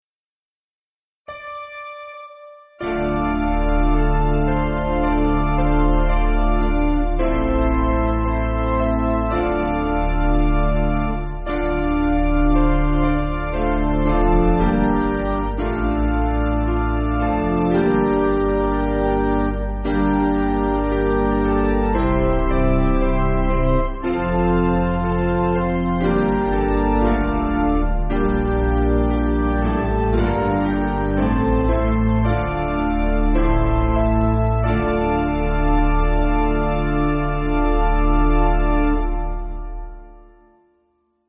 Basic Piano & Organ
(CM)   1/Dm